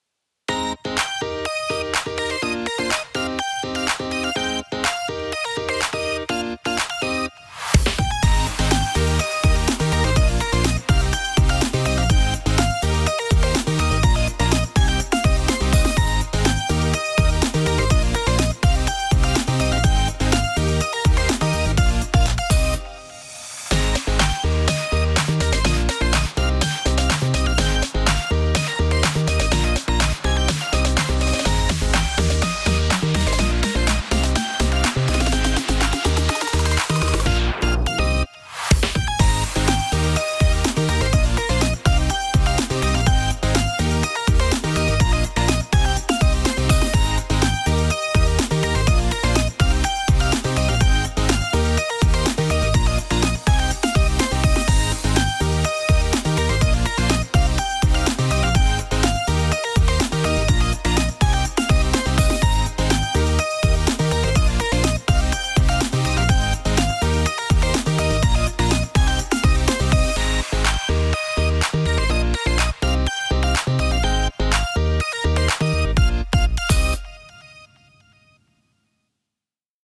明るい/EDM/オープニング/かわいい/疾走感